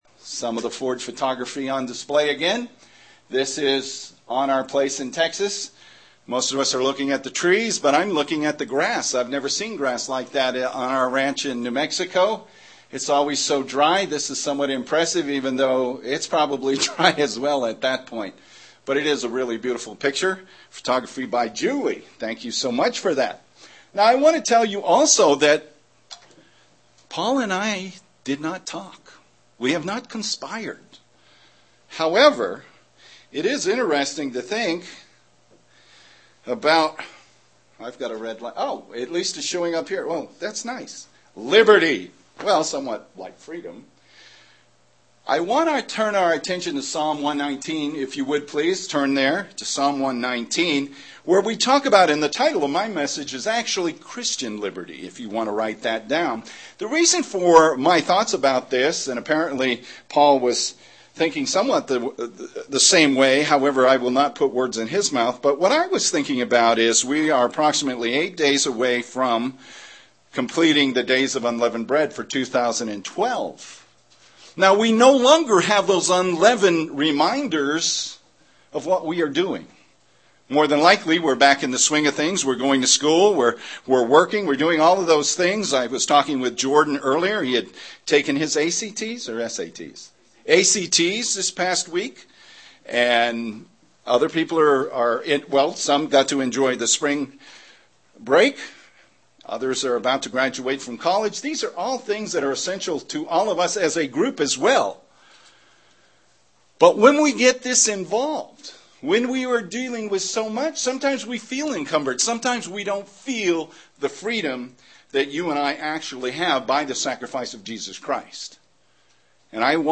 Given in Albuquerque, NM
UCG Sermon Studying the bible?